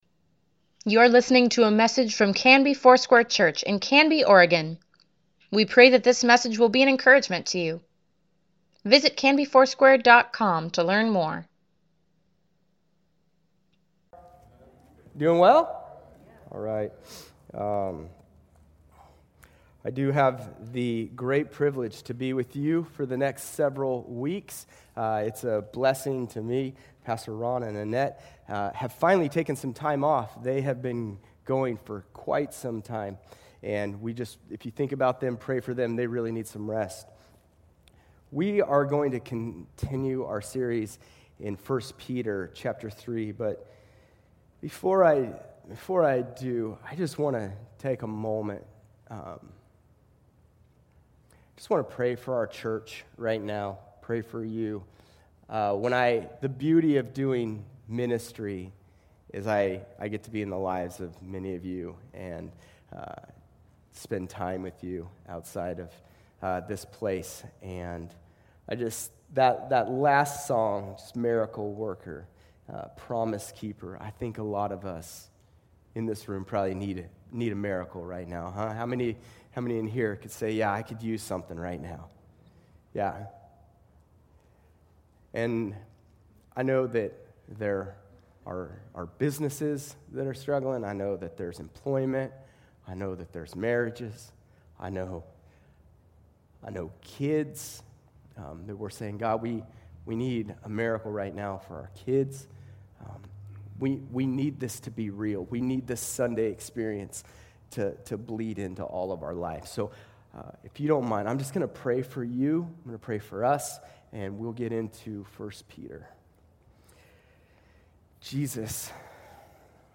Weekly Email Water Baptism Prayer Events Sermons Give Care for Carus Steadfast, pt. 7 July 19, 2020